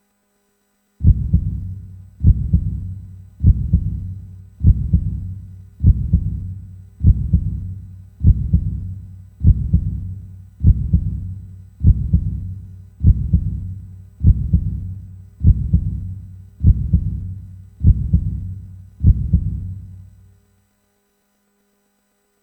Dramatic Heartbeat Sound Effect Free Download
Dramatic Heartbeat